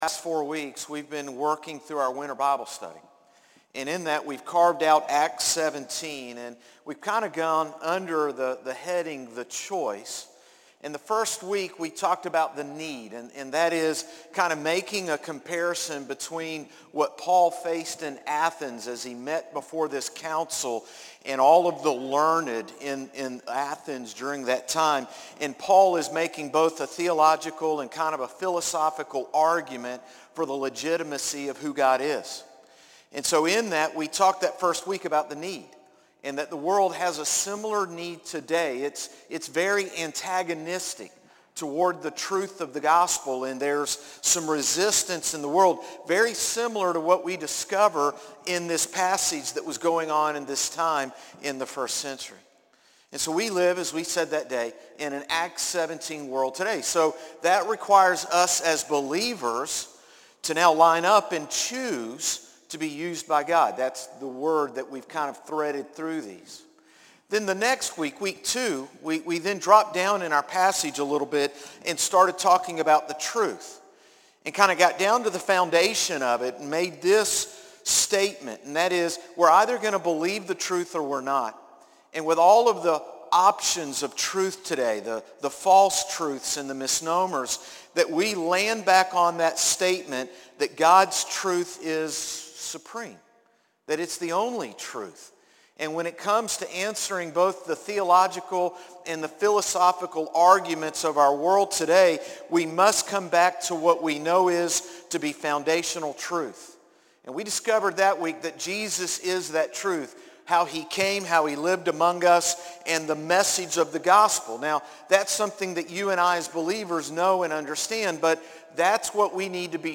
Sermons - Concord Baptist Church
Morning-Service-2-25-24.mp3